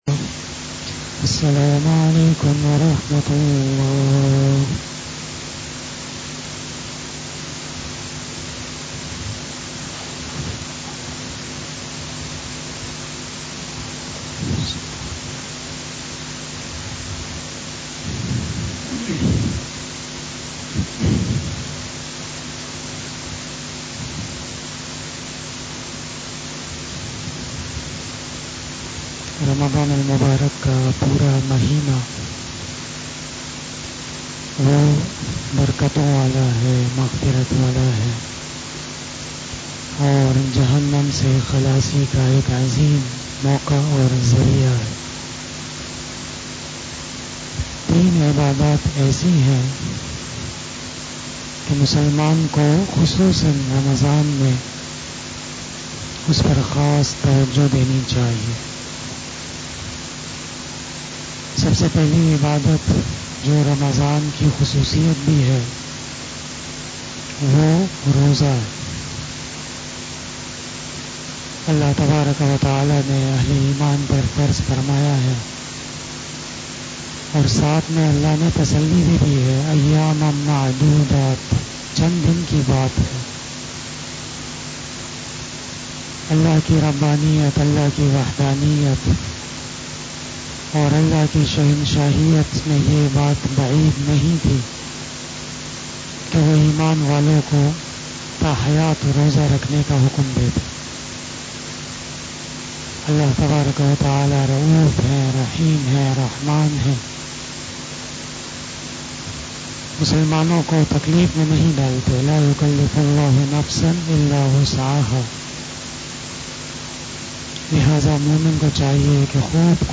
After Asar Namaz Bayan
بیان بعد نماز عصر